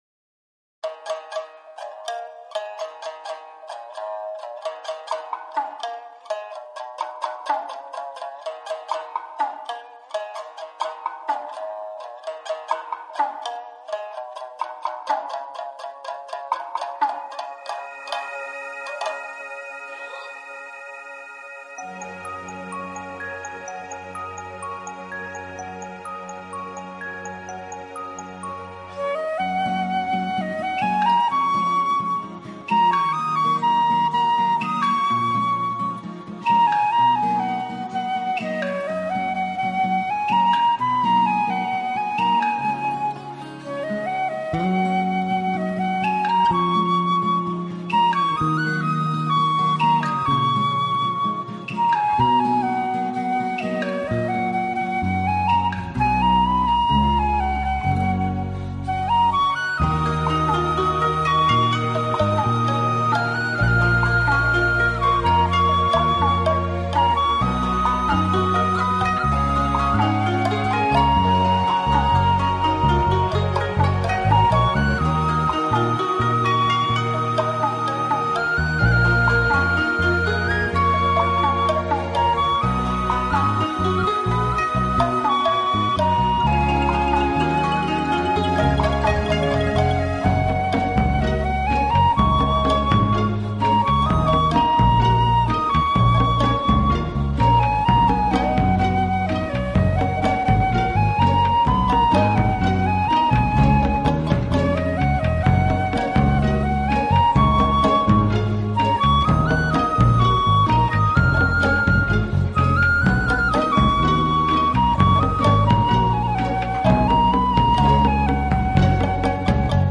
本专辑在东京和大坂录制，且融合了两个日本的传统乐器和 常见的西方乐器。
NewAge